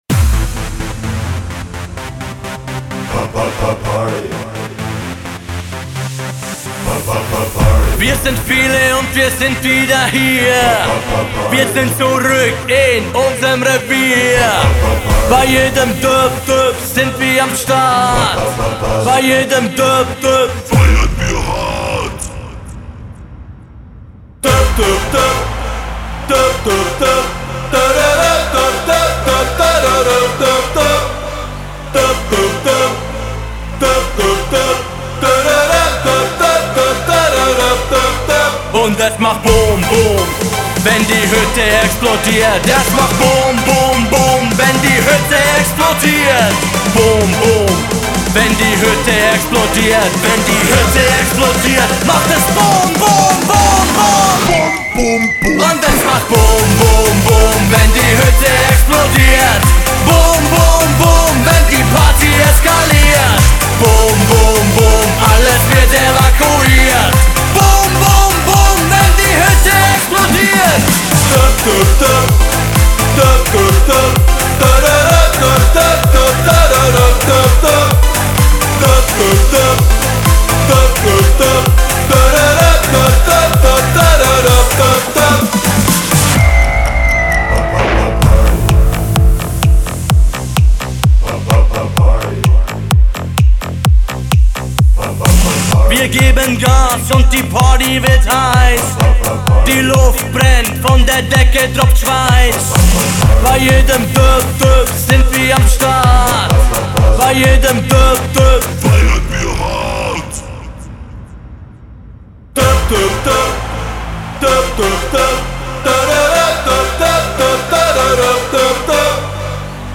Aprés Ski Hit 2019